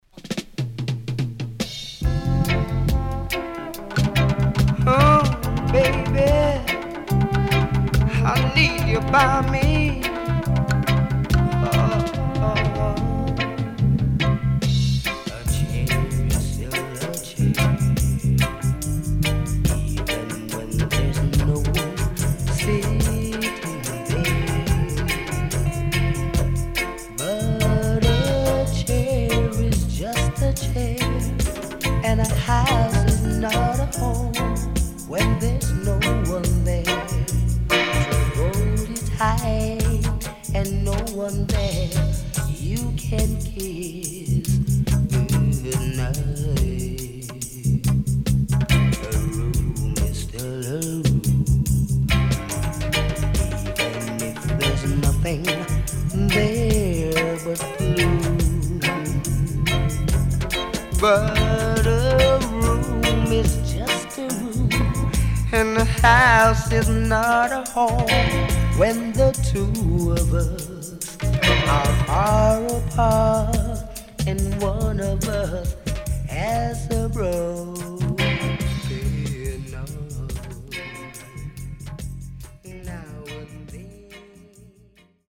【12inch】